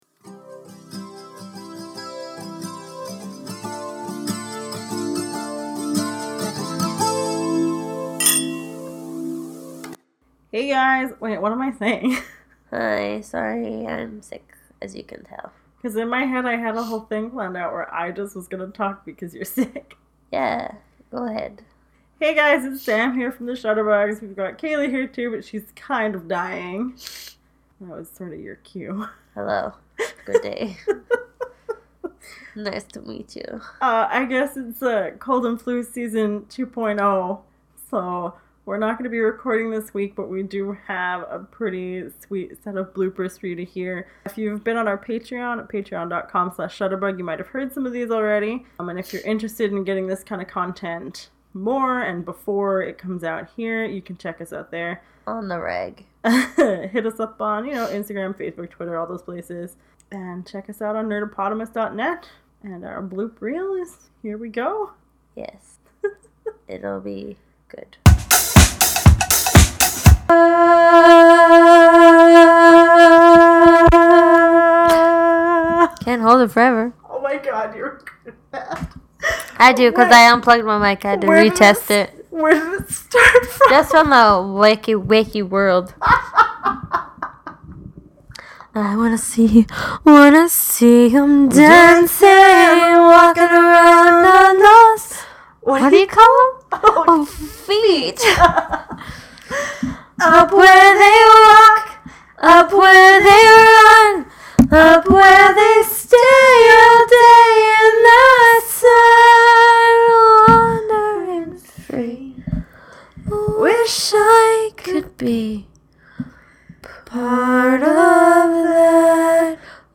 blooper-week-apr-9.mp3